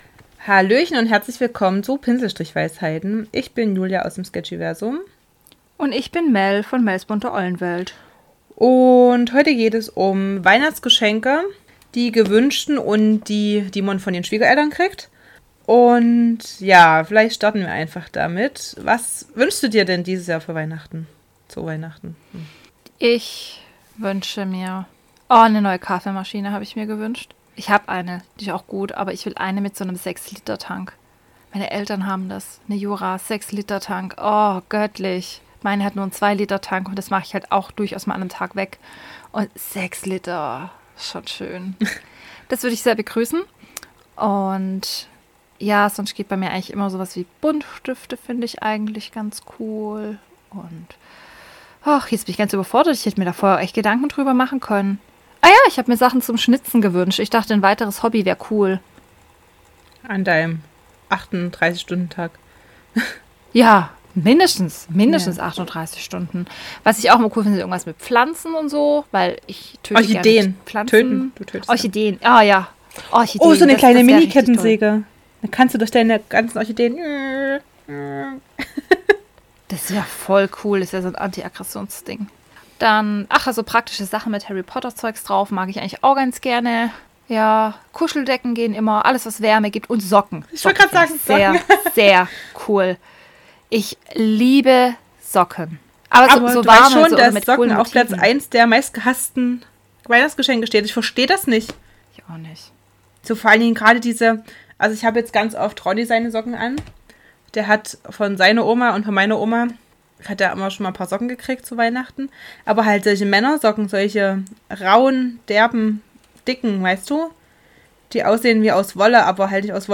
Außerdem geht's um coole Geschenkideen für Minecraft-Fans! Diesmal gibt es weniger Fakten, dafür aber jede Menge Humor, Lacher und festliche Stimmung.